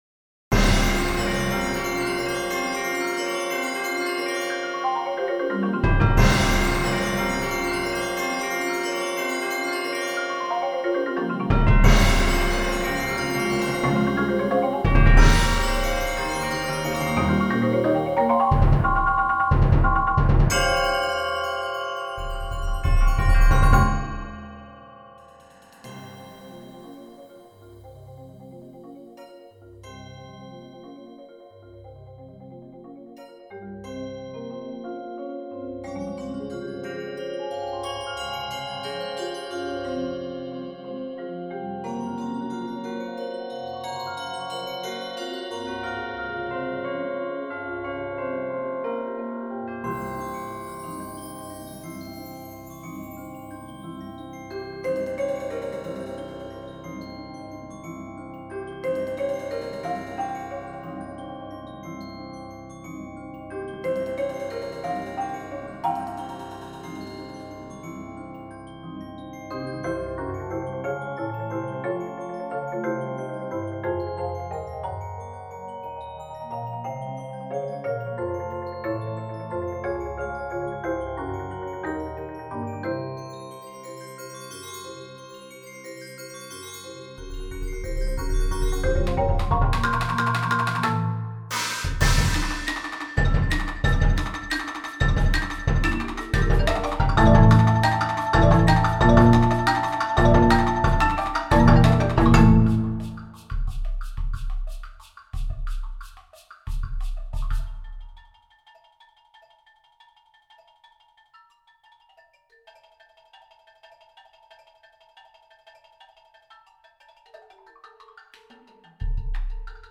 Voicing: 12 Percussion